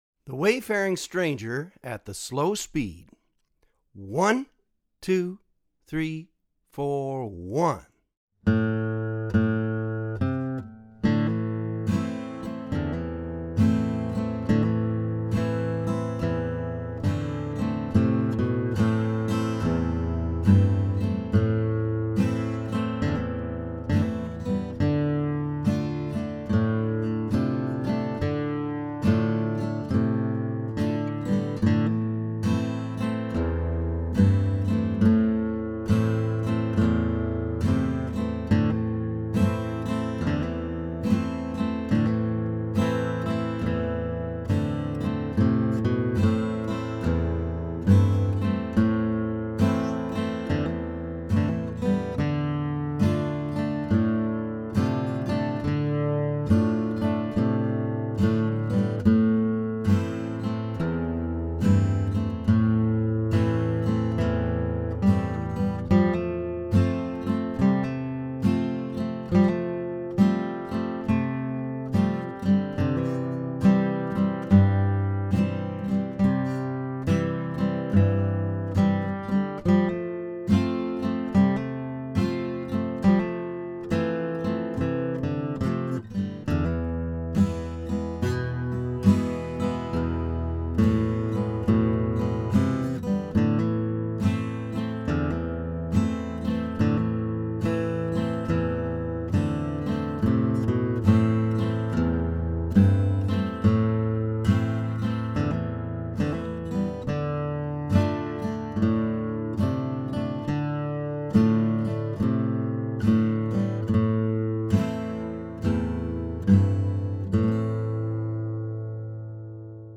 DIGITAL SHEET MUSIC - FLATPICK GUITAR SOLO
Online Audio (both slow and regular speed)